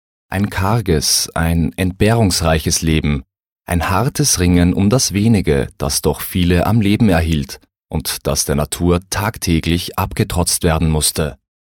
Sympathisches, mittleres/dunkles Timbre, vielseitig einsetzbar.
Sprechprobe: Sonstiges (Muttersprache):